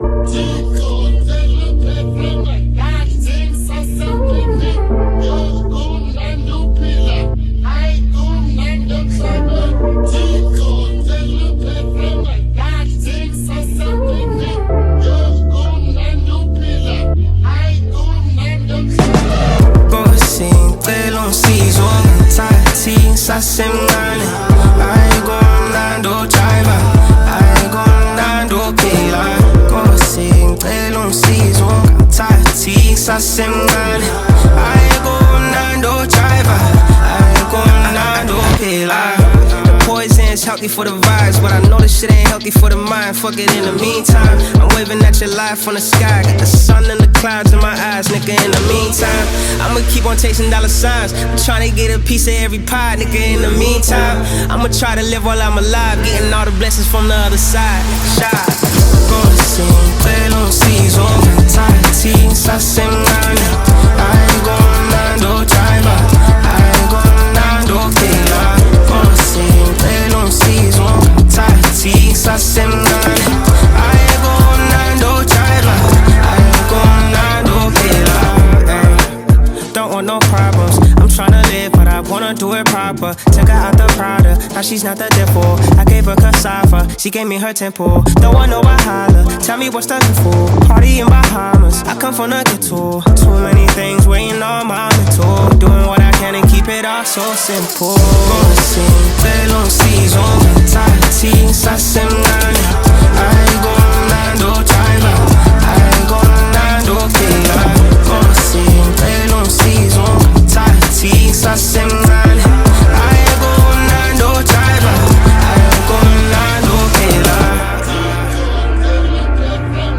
smooth vocals, the catchy hooks
blends Afrobeat with contemporary sounds
With its high energy tempo and catchy sounds